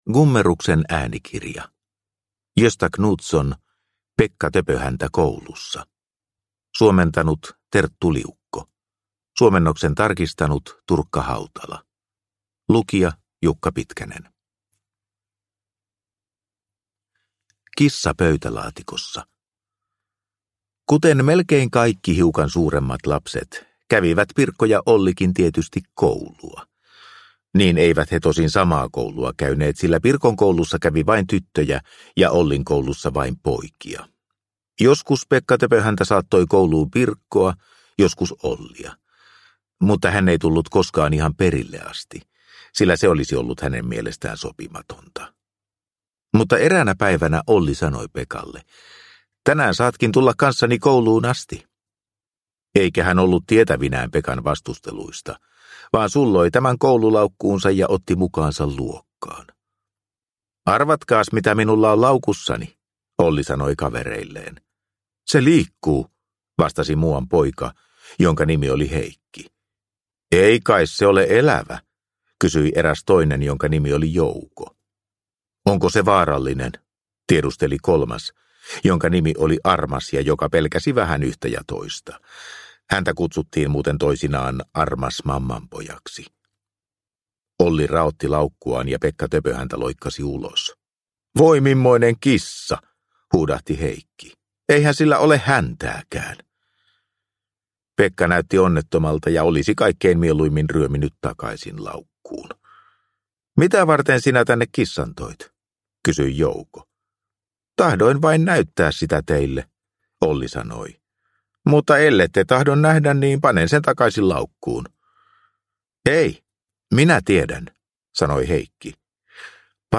Pekka Töpöhäntä koulussa – Ljudbok – Laddas ner